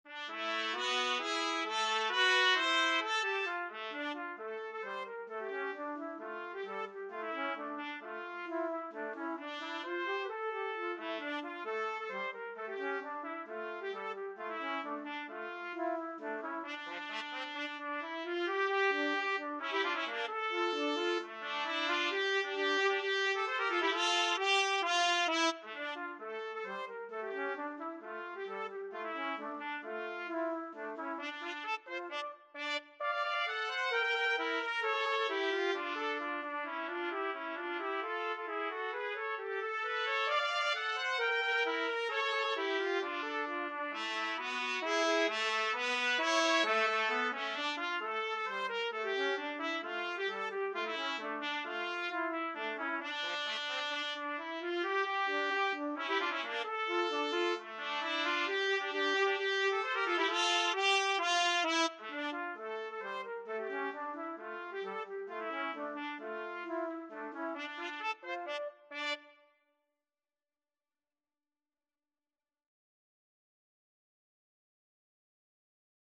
Trumpet 1Trumpet 2
D minor (Sounding Pitch) E minor (Trumpet in Bb) (View more D minor Music for Trumpet Duet )
2/4 (View more 2/4 Music)
Trumpet Duet  (View more Intermediate Trumpet Duet Music)
Traditional (View more Traditional Trumpet Duet Music)